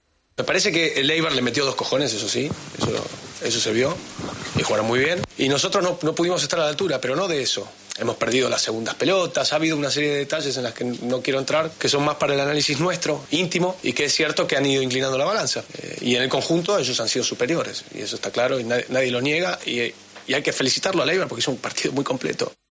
El entrenador del Real Madrid habló después de la dura derrota 3-0 ante el Eibar.